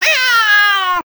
sounds / monsters / cat / 4.ogg